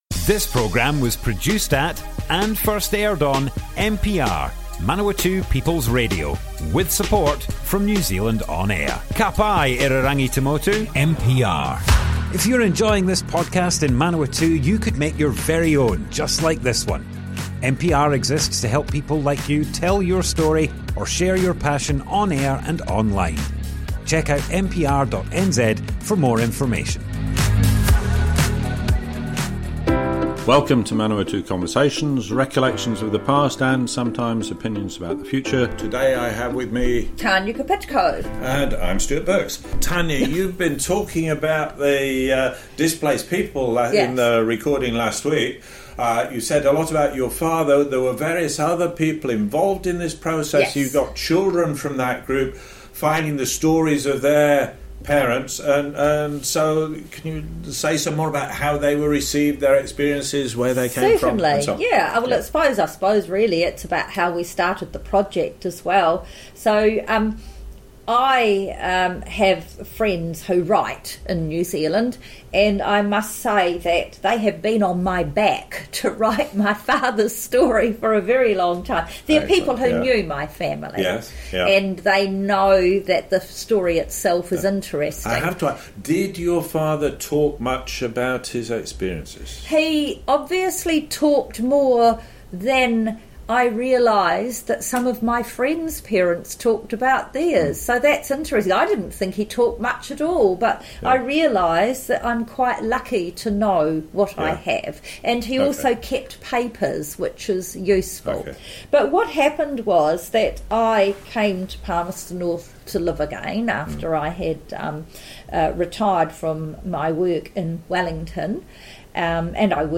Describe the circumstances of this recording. Manawatu Conversations More Info → Description Broadcast on Manawatu People's Radio, 4th June 2024.